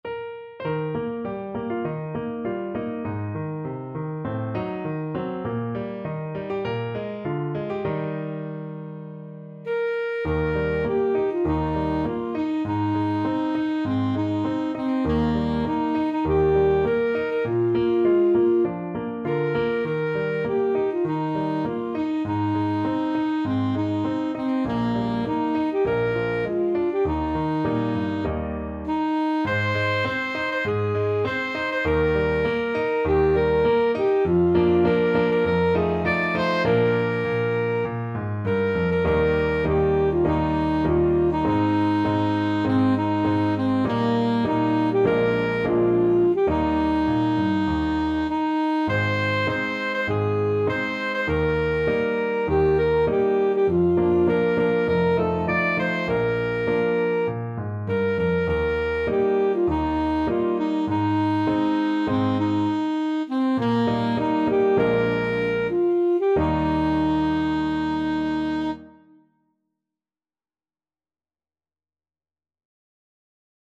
Alto Saxophone
Eb major (Sounding Pitch) C major (Alto Saxophone in Eb) (View more Eb major Music for Saxophone )
~ = 100 Moderato
4/4 (View more 4/4 Music)
Bb4-D6
young_maggie_ASAX.mp3